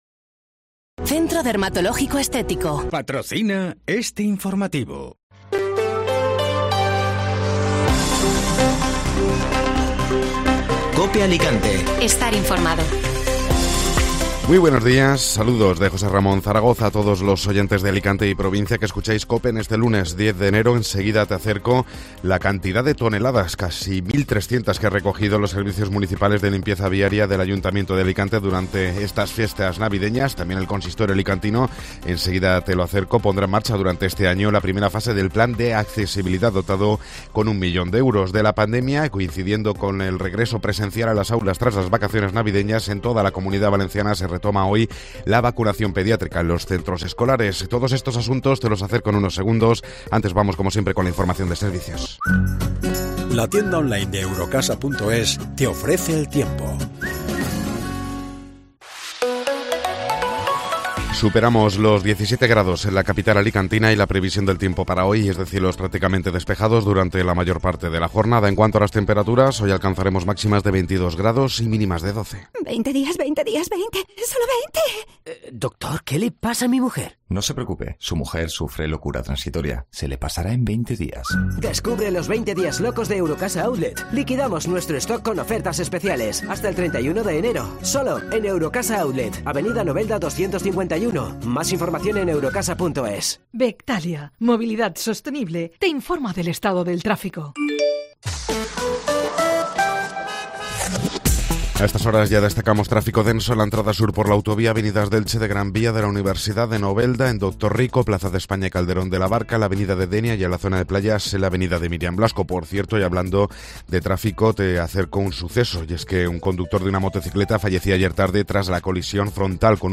Informativo Matinal (Lunes 10 de Enero)